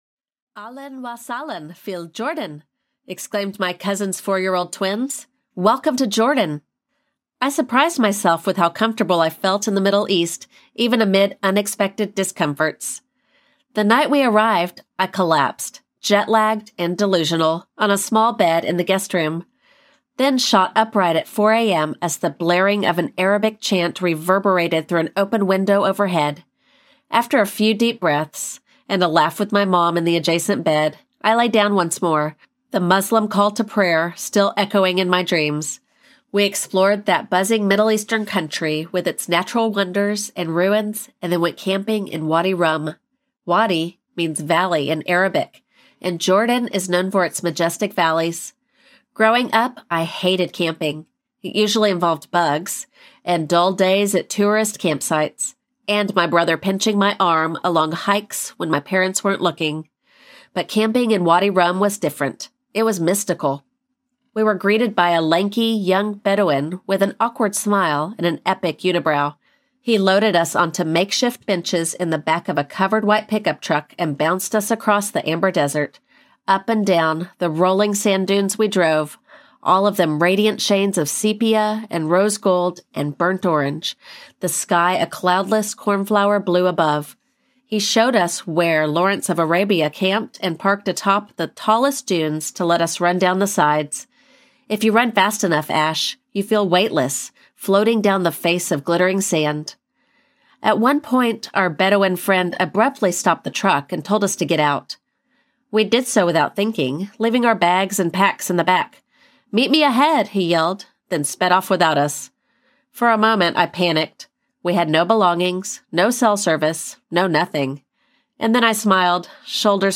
Twenty-Two Audiobook
6.78 Hrs. – Unabridged